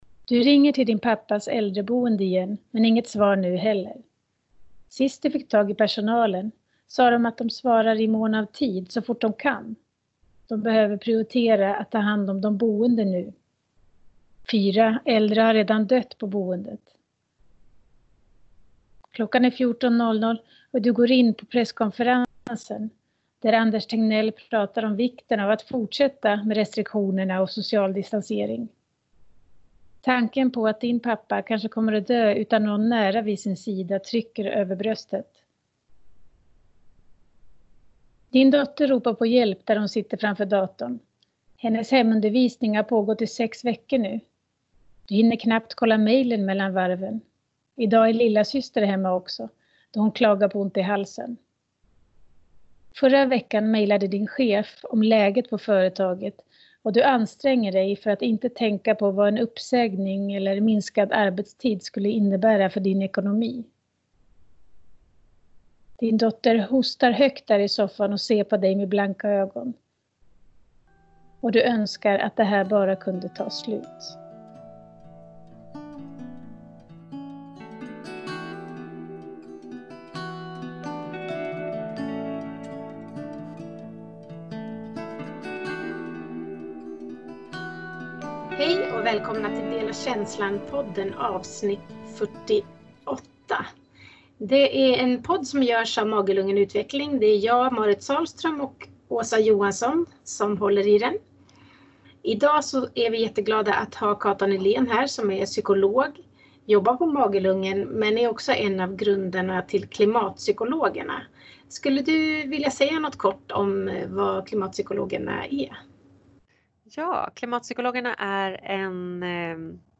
Detta avsnitt spelas in på distans och vi hoppas ni har överseende med ljudet.